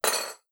Impacts
clamour11.wav